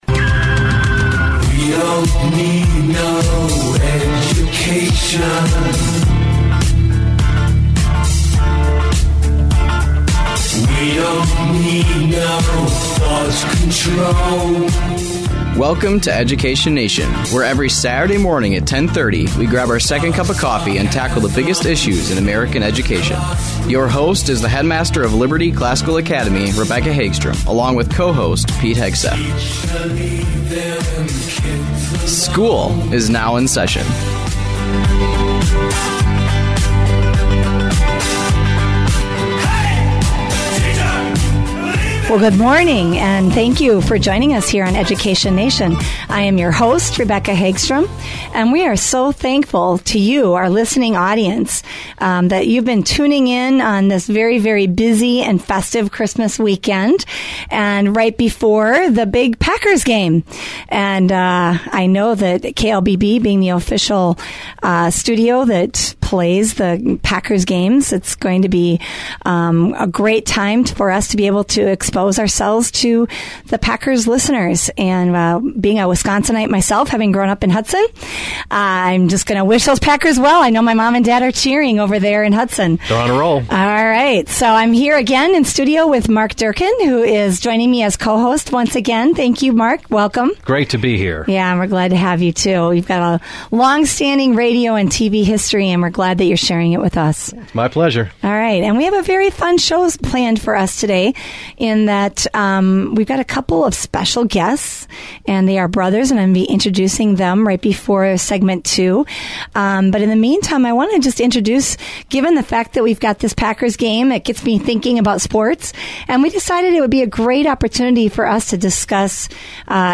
The hosts also interview two former Liberty Classical Academy students and have them reflect on both their athletic and academic experiences at Liberty.